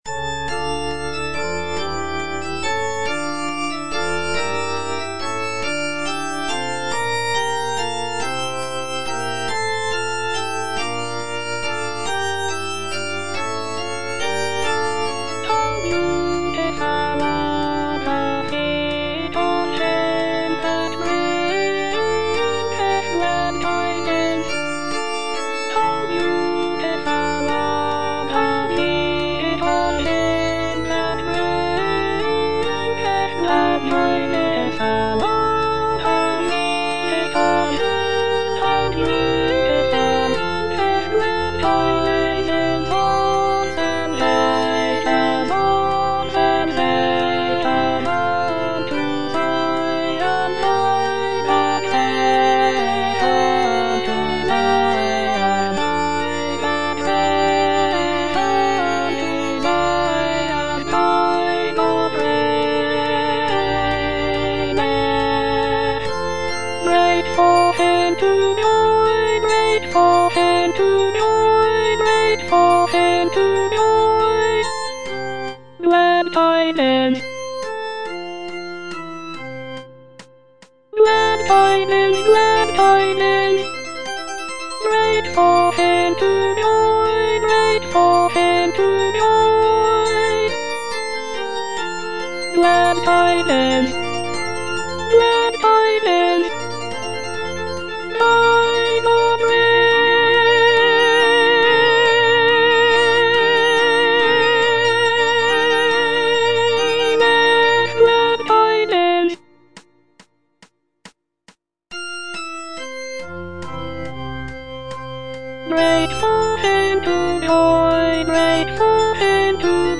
G.F. HÄNDEL - HOW BEAUTIFUL ARE THE FEET OF HIM FROM "MESSIAH" (DUBLIN 1742 VERSION) Alto (Voice with metronome) Ads stop:
The piece features a solo soprano or tenor singing about the beauty of the messengers who bring good news and preach the gospel of peace.